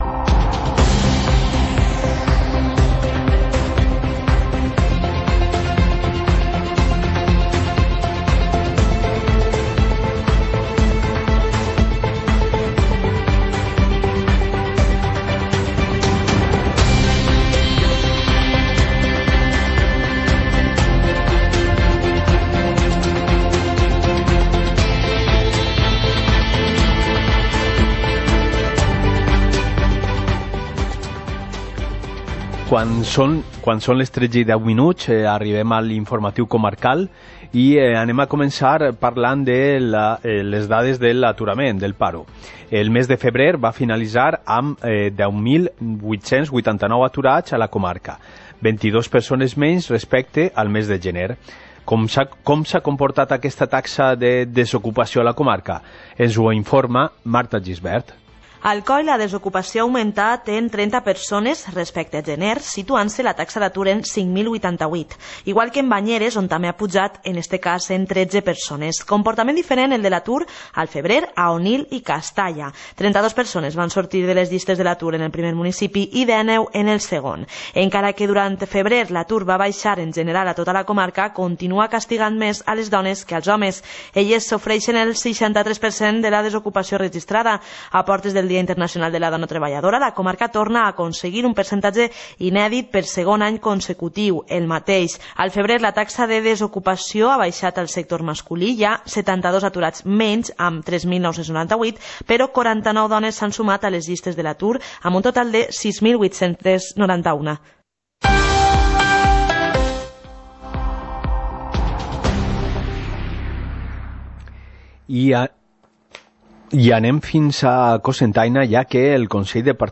Informativo comarcal - miércoles, 06 de marzo de 2019